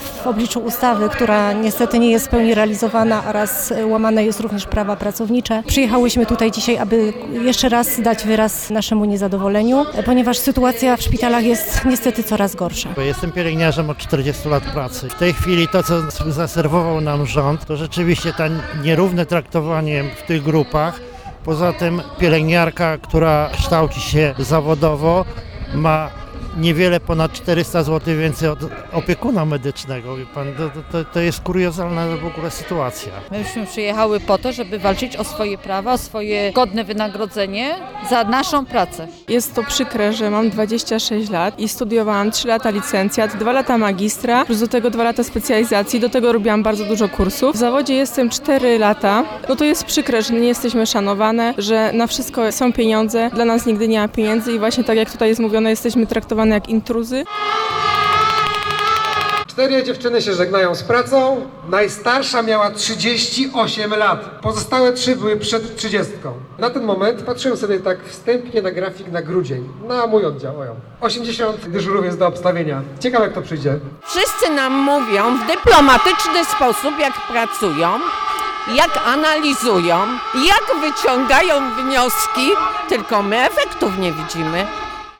Dziś protestowały przed Kancelarią Premiera w Warszawie. Ogólnopolski Związek Zawodowy Pielęgniarek i Położnych alarmuje, że młode pokolenie wybiera prywatne firmy medyczne, w obawie o przyszłość.